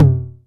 • Low Mid TR 909 Electronic Tom Sample C Key 47.wav
Royality free tr 909 analog tom tuned to the C note. Loudest frequency: 206Hz
low-mid-tr-909-electronic-tom-sample-c-key-47-hde.wav